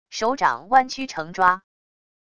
手掌弯曲成抓wav音频